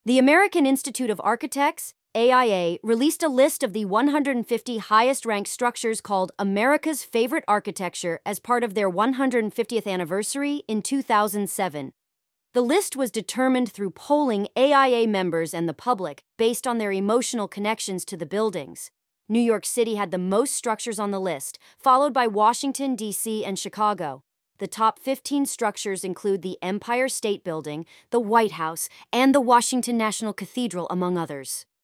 Quick Summary Audio